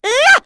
Chrisha-Vox_Attack3.wav